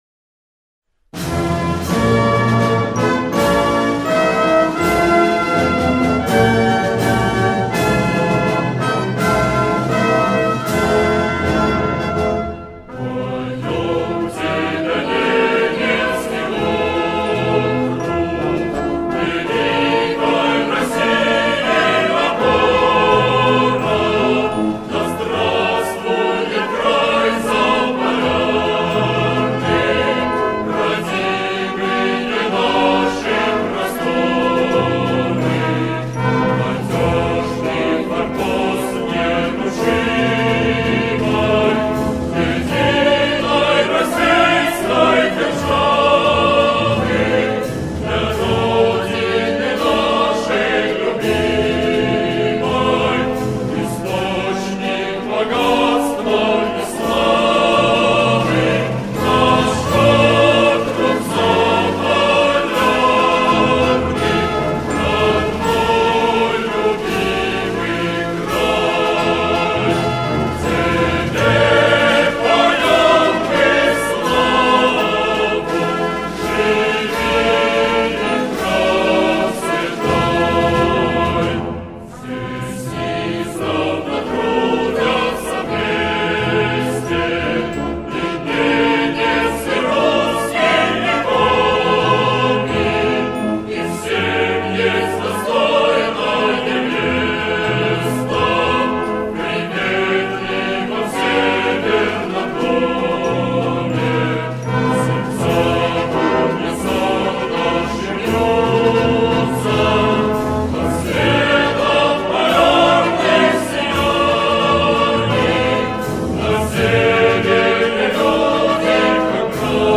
величественное музыкальное произведение
со словами